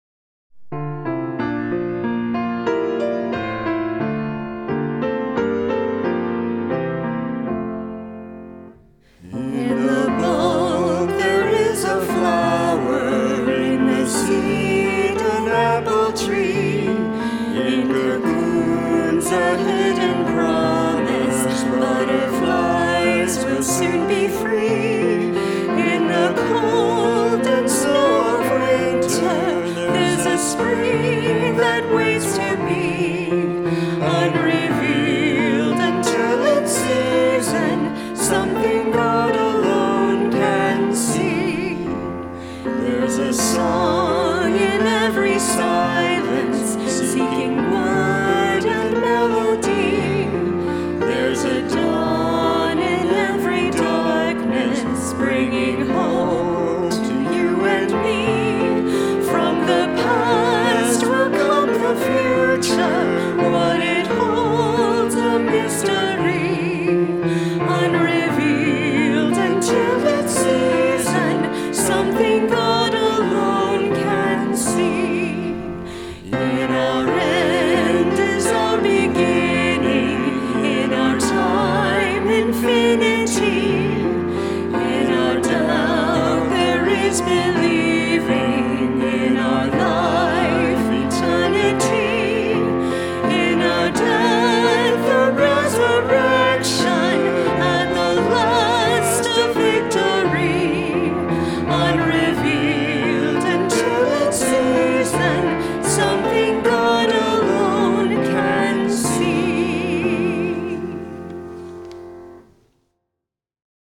Service of Worship
Opening Hymn — #707 Hymn of Promise